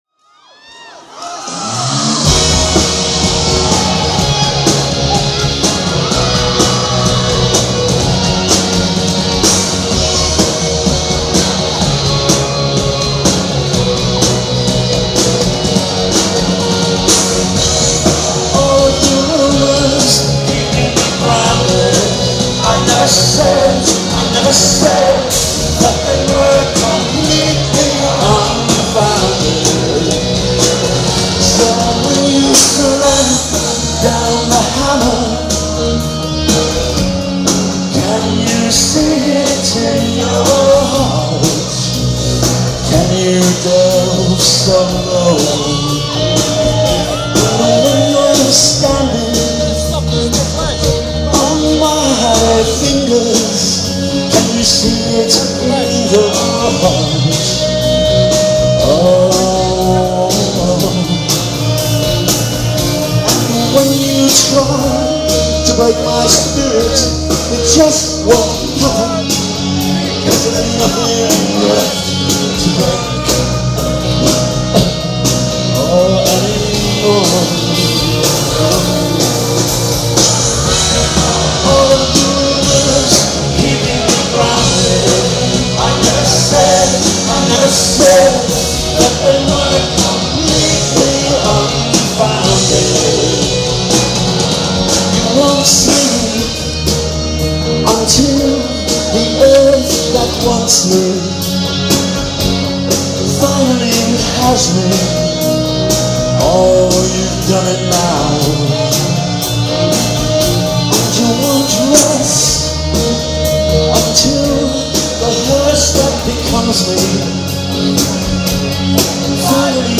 自分で録音したやつと、イアホンで聞き比べてみるといいかも。